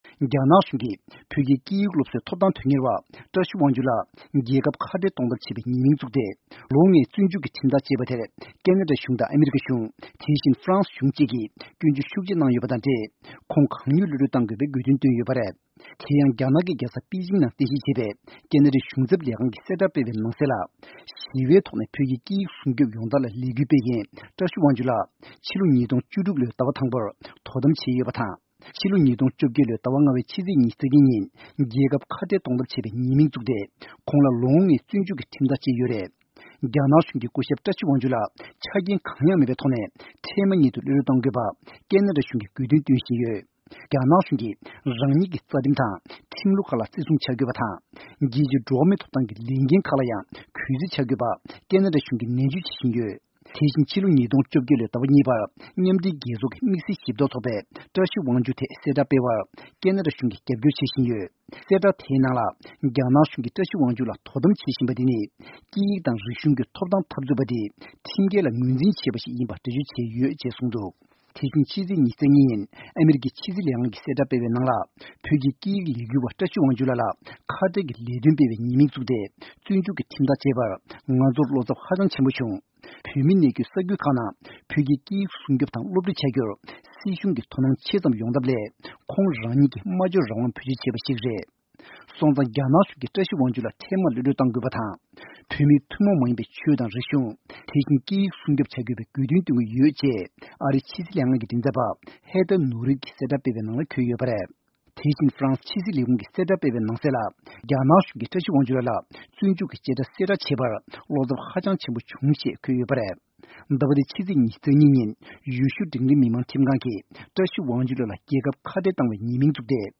གནས་ཚུལ་སྙན་སྒྲོན་